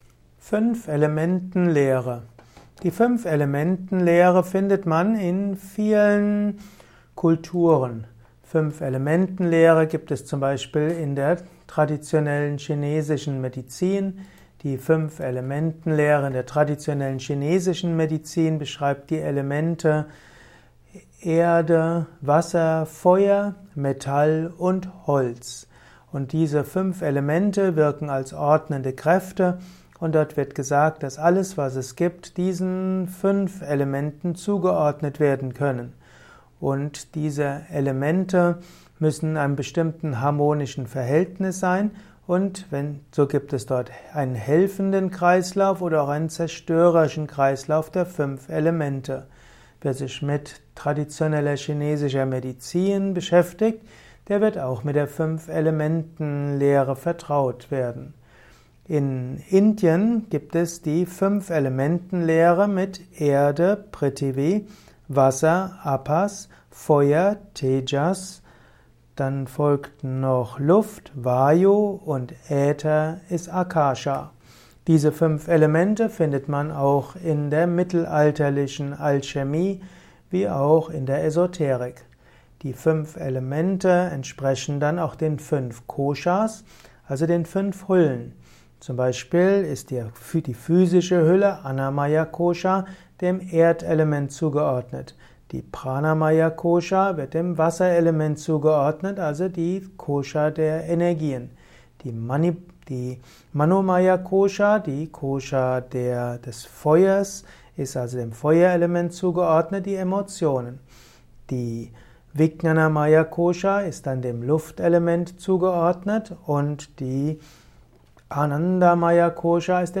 Ein Kurzvortrag über die Fünf-Elementen-Lehre